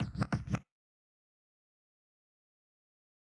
Astronauts DJ Scratch.wav